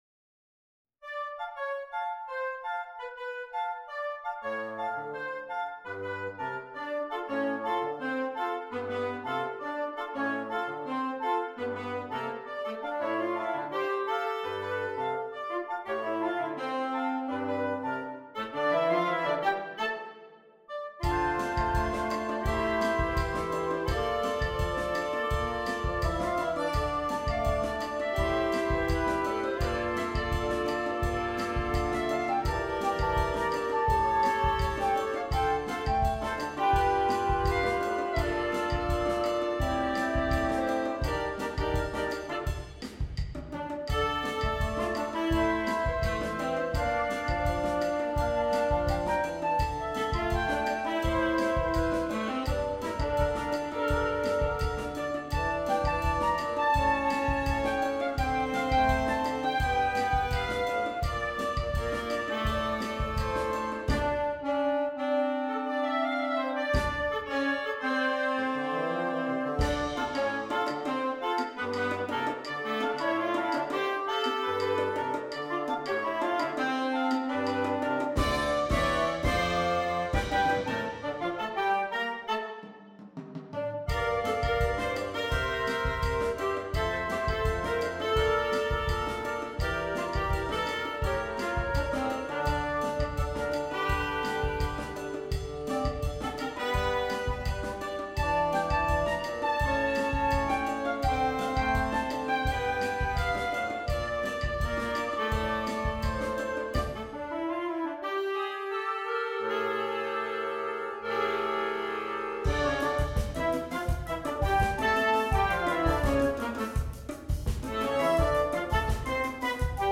Interchangeable Woodwind Ensemble
PART 1 - Flute, Oboe, Clarinet
PART 4 - Clarinet, Alto Saxophone, Tenor Saxophone, F Horn
PART 5 - Bass Clarinet, Bassoon, Baritone Saxophone